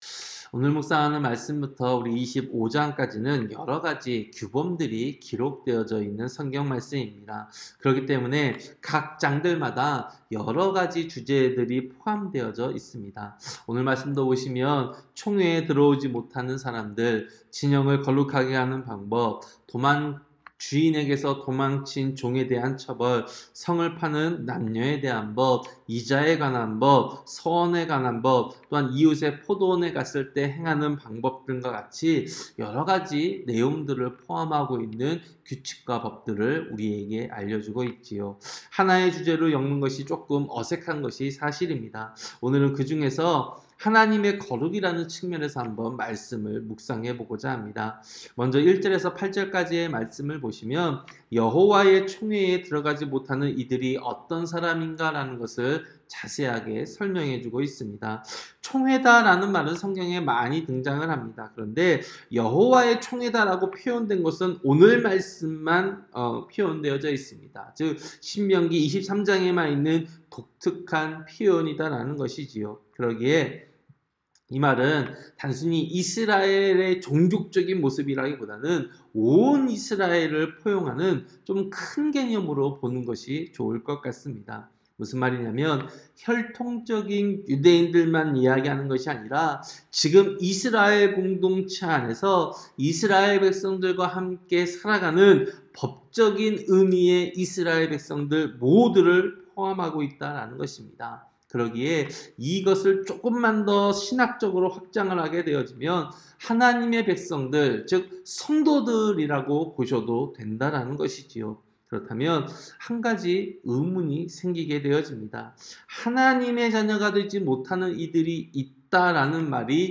새벽설교-신명기 23장